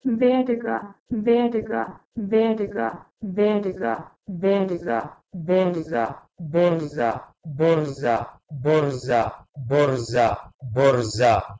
PGmc-berga-to-Balochi-borza.wav